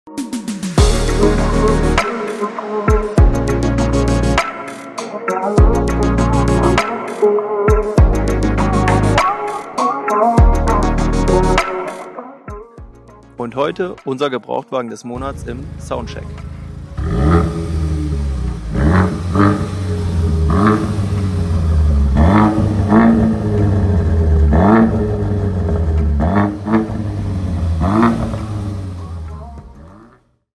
🎧 Sound an – Gänsehautmodus sound effects free download By autohaustenambergen 1 Downloads 2 months ago 30 seconds autohaustenambergen Sound Effects About 🎧 Sound an – Gänsehautmodus Mp3 Sound Effect 🎧 Sound an – Gänsehautmodus aktiv! Der Opel Astra J GTC OPC liefert nicht nur optisch ab – sein Klang spricht eine eigene Sprache. 🔊 Infinity Sound 🔥 OPC-Performance 🖤 Recaro Sportsitze in Nappa-Leder ⚙ 280 PS – bereit für die Landstraße 👉 Jetzt Probe hören – und bald Probe fahren?